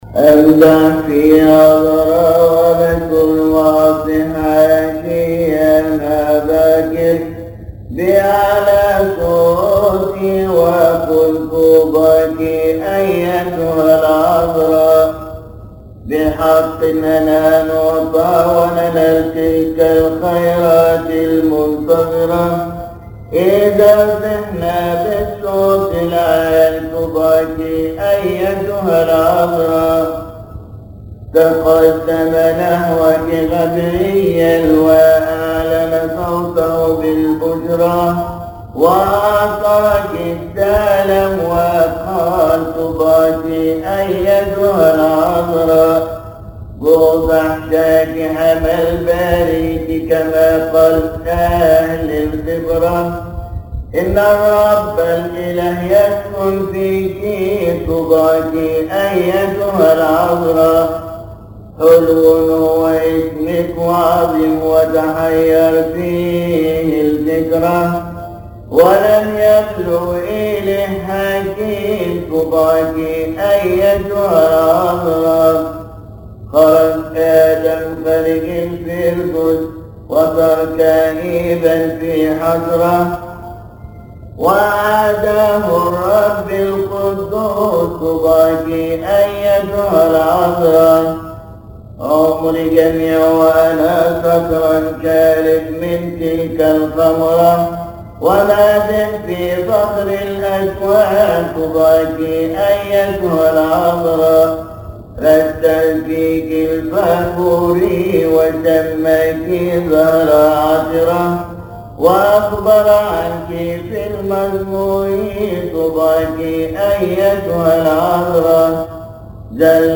أمدح في عذراء وبتول (مديح كيهكي علي الإبصالية واطس)